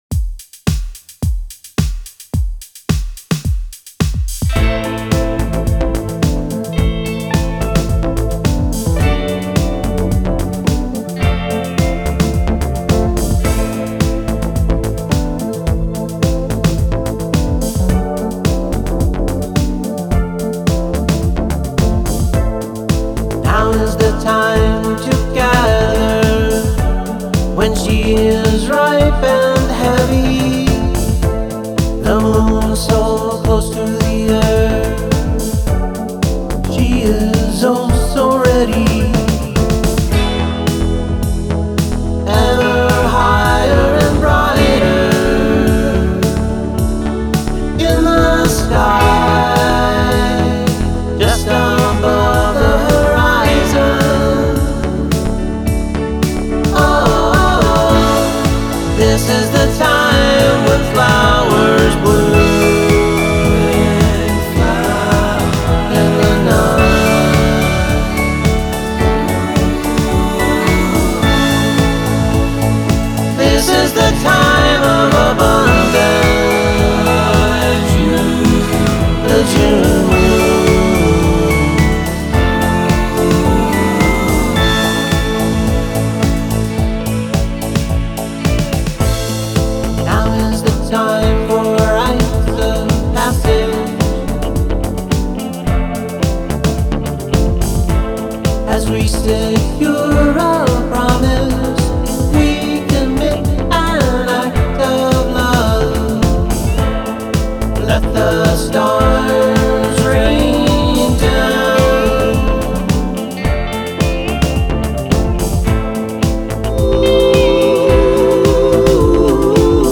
Loving the synthy goodness on show here.